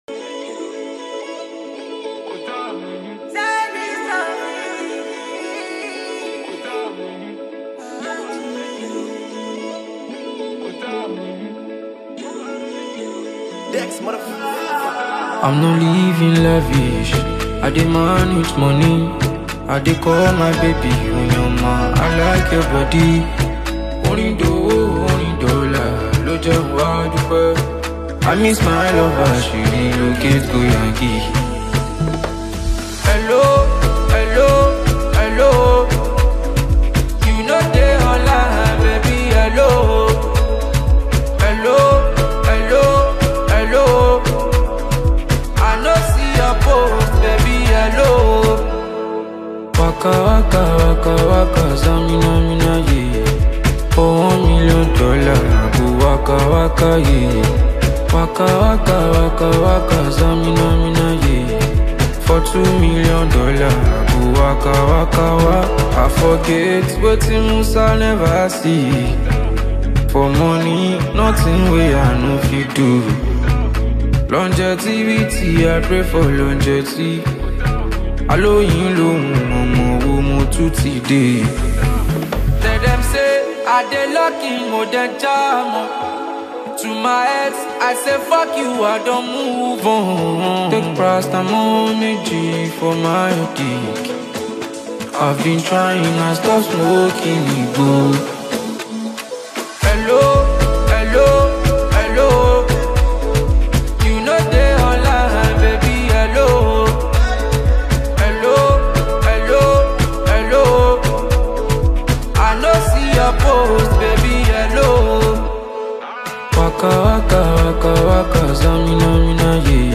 Enjoy this brilliant tune from a gifted Nigerian singer